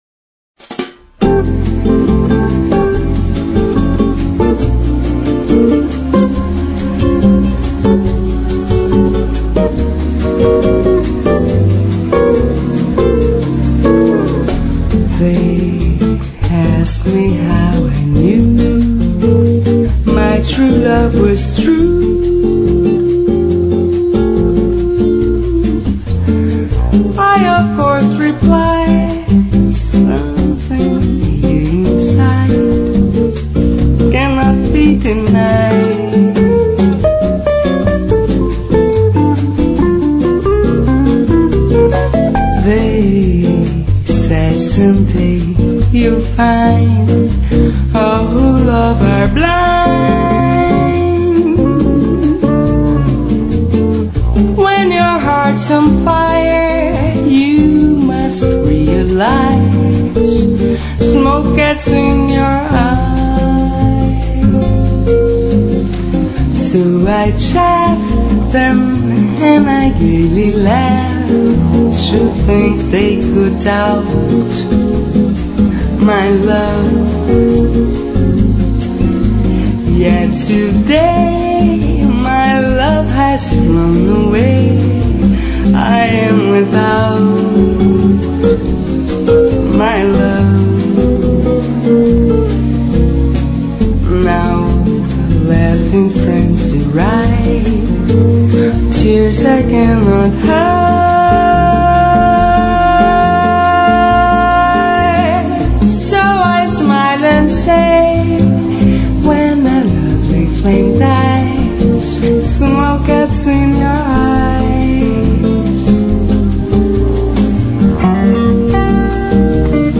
Bossa Version